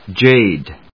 /dʒéɪd(米国英語), dʒeɪd(英国英語)/